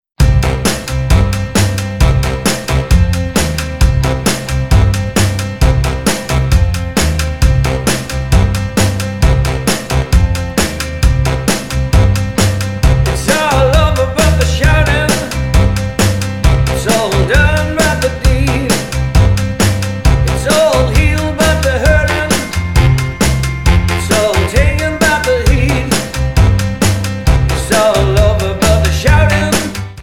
Tonart:Cm Multifile (kein Sofortdownload.
Die besten Playbacks Instrumentals und Karaoke Versionen .